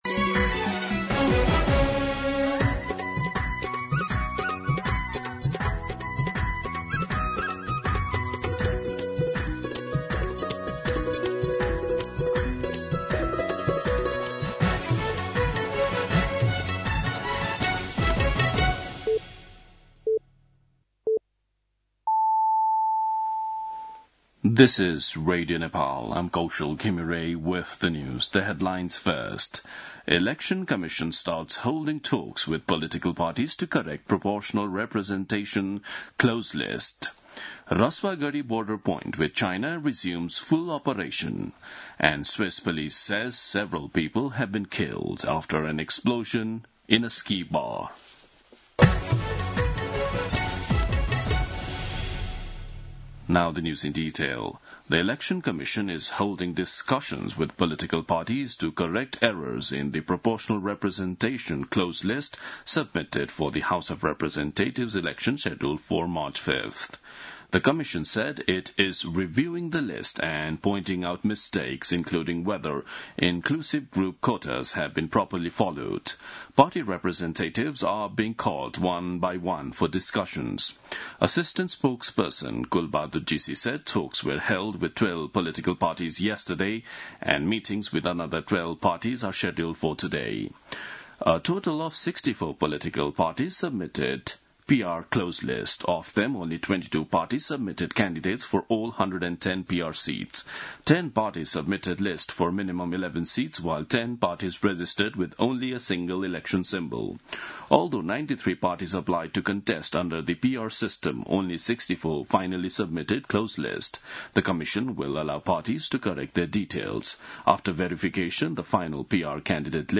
दिउँसो २ बजेको अङ्ग्रेजी समाचार : १७ पुष , २०८२
Day-News-2-pm-9-17.mp3